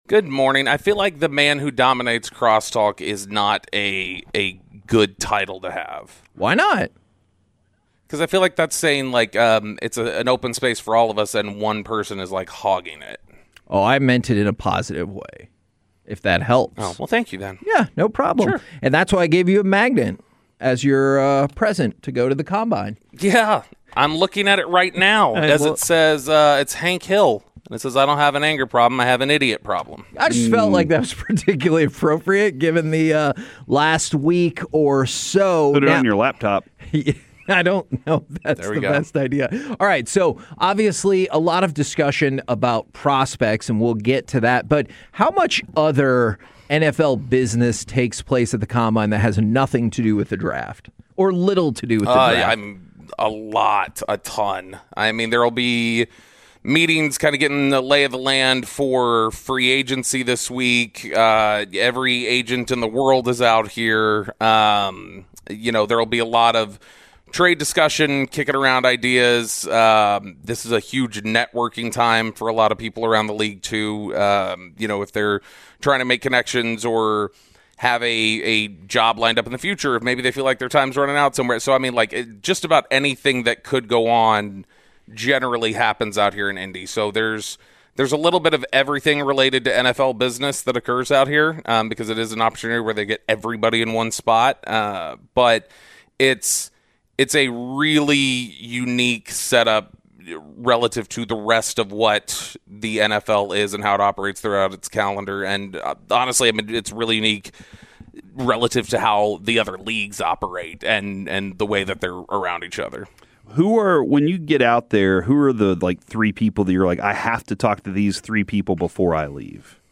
Live from the NFL Combine